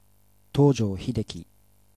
Hideki Tojo (東條 英機, Tōjō Hideki, pronounced [toːʑoː çideki]